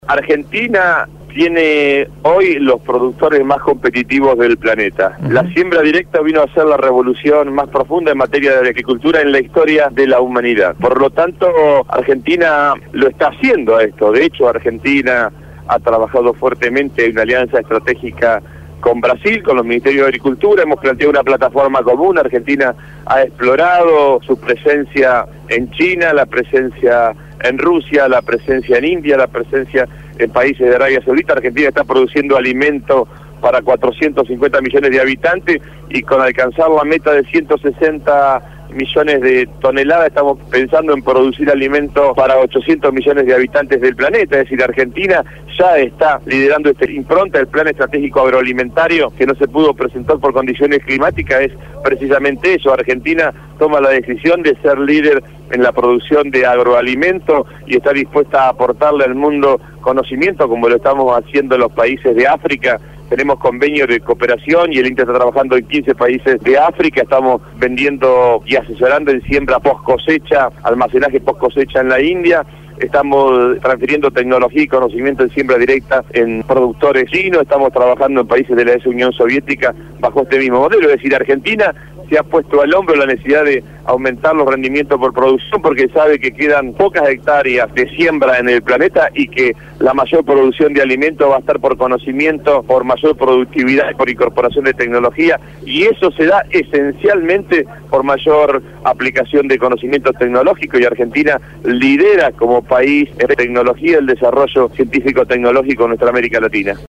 El Ministro de Agricultura de la Nación y candidato a Diputado Nacional por la Provincia de Buenos Aires acompañó a Cristina Fernandez en la Fiesta del Maíz en Chacabuco.